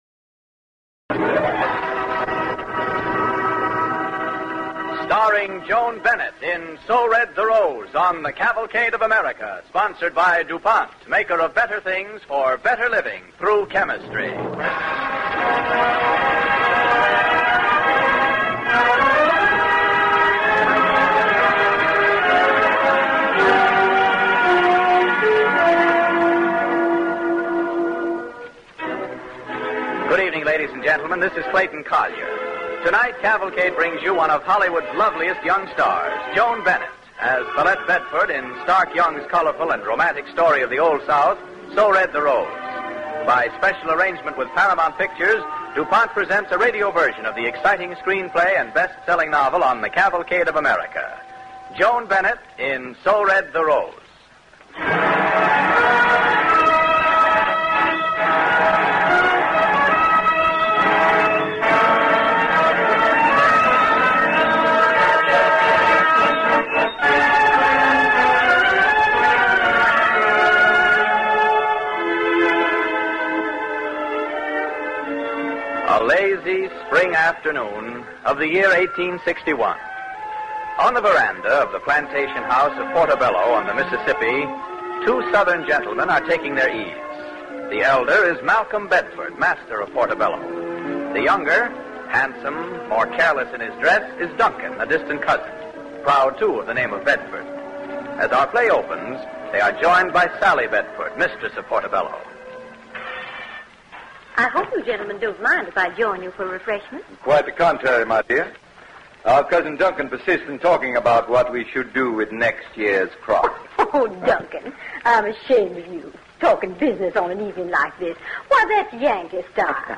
Cavalcade of America Radio Program